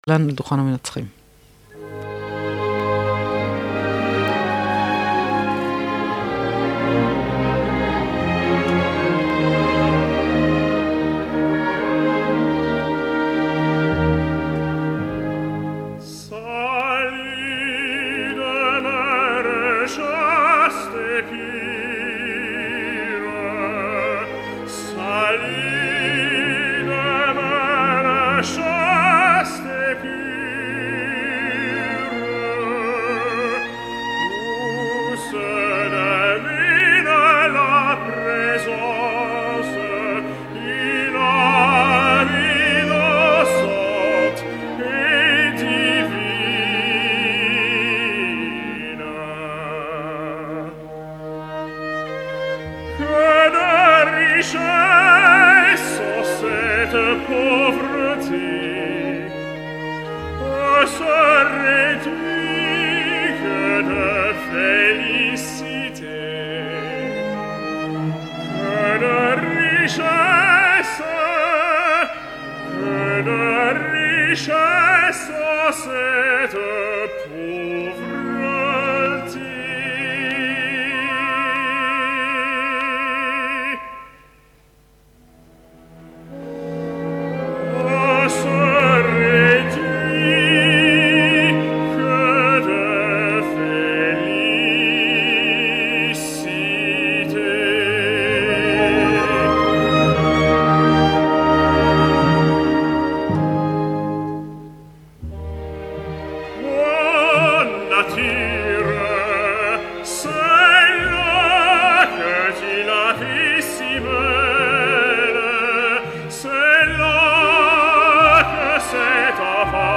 L’escoltarem en àries prou conegudes, del gran repertori i en una gravació provinent d’un concert en directa, per tant podreu apreciar les virtuts d’una veu in un cantant, de la mateixa manera que evidenciareu que no tot està assolit i que encara queden coses per polir.
El so de la retransmissió i les condicions tècniques (saturació i altres inconvenients) no són un gran què i per aquest motiu he decidit no deixar-vos cap enllaç del concert, però si que he cregut convenient deixar-vos escoltar unes quantes àries per fer-nos una composició de lloc.
Ara us proposo escoltar a Hymel en l’ària del tercer acte de Faust “Quel trouble inconnu me penetre?…Salut! Demeure chaste et pure”. Curiosament aquí li trobo a faltar un registre greu una mica més consolidat en la primera part de l’ària, mentre que quan la melodia pren volada la veu de Hymel brilla. Potser una mica més de poètica intenció no li hagués anat malament en aquesta interpretació, però quan arriba l’agut el resultat és espectacular.
Tots els fragments provenen d’un concert celebrat el dia 9 de gener de 2013 a Jerusalem, l’orquestra està dirigida per Frédéric Chaslin